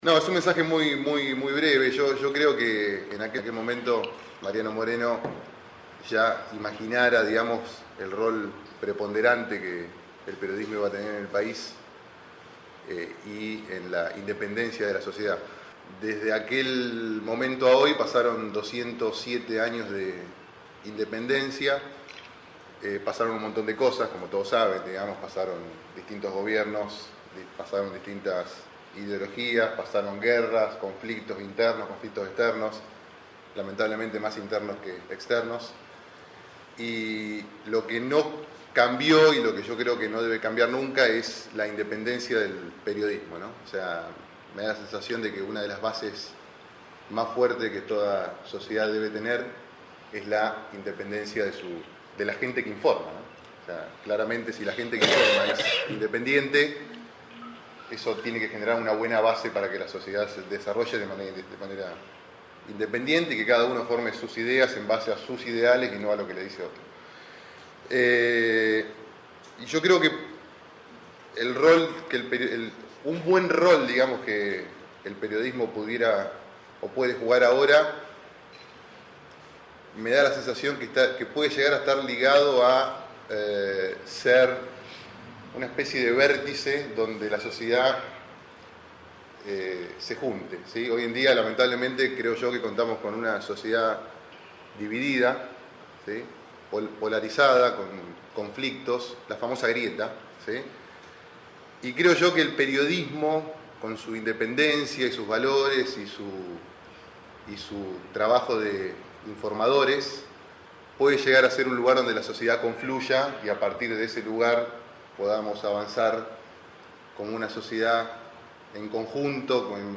Al finalizar el almuerzo y antes del brindis final, fueron escuchadas las palabras
Palabras alusivas